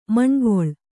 ♪ maṇgoḷ